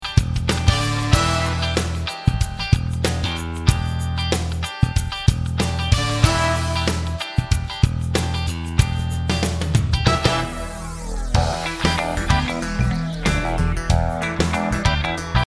1 – Full Version With Guide Drum Track